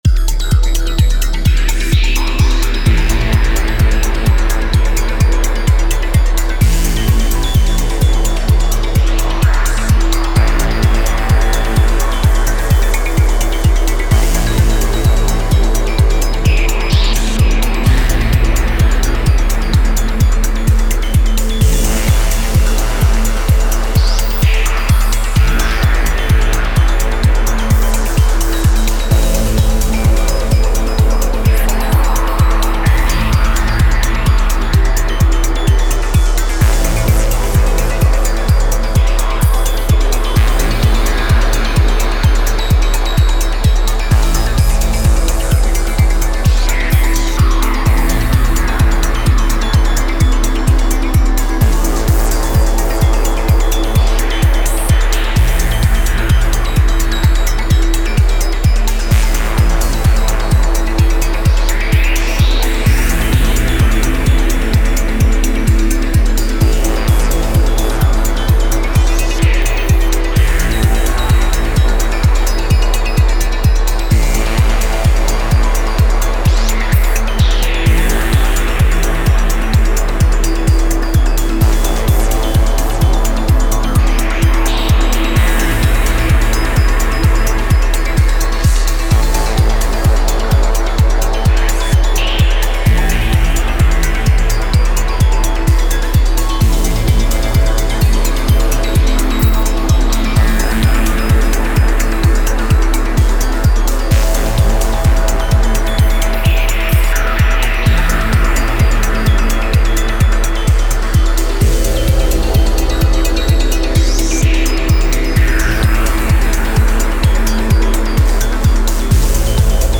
Stereo phasing and spring reverb, Quadraverb GT, and some Valhalla and Soundtoys plugins.
(work in progress loop, please excuse the sloppiness :space_invader:)